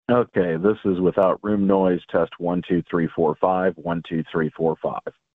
The standard phone call, typically utilizing G.711 codec, operates within a narrowband frequency range of 300 Hz to 3.4 kHz.
High and low-frequency sounds are often lost, making voices sound less natural and more compressed.
Not HD Calling